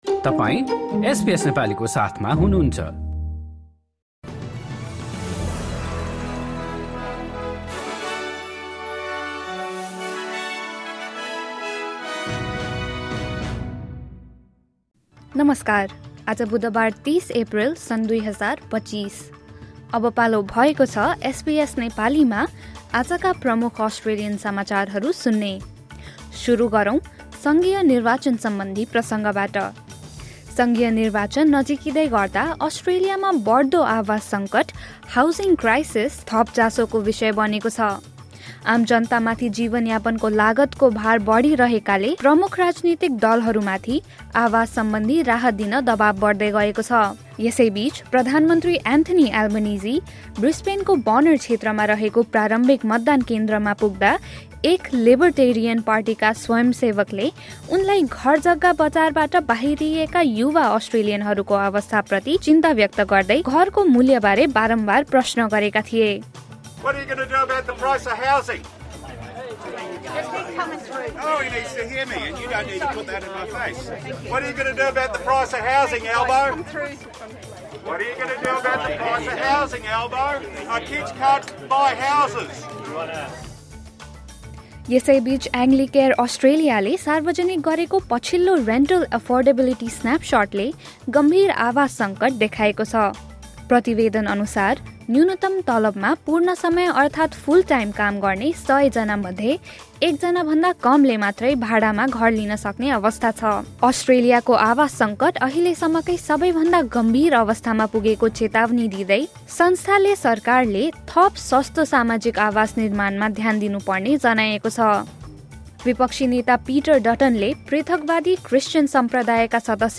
एसबीएस नेपाली प्रमुख अस्ट्रेलियन समाचार: बुधवार, ३० एप्रिल २०२५